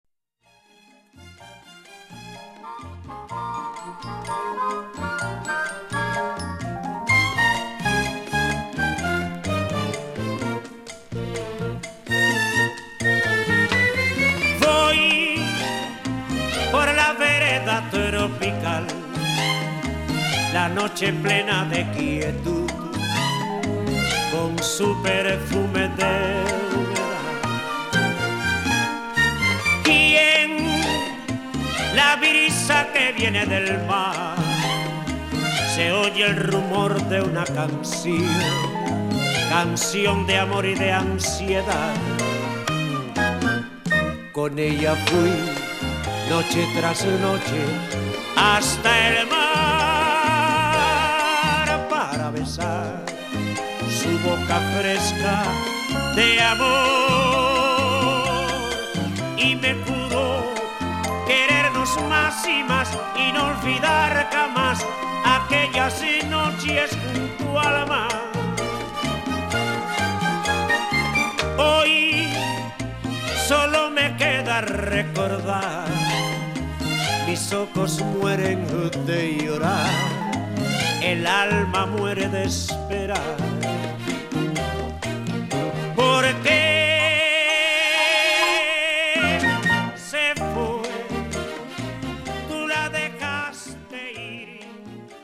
オールド・ハバナの息吹がそのままに録音されている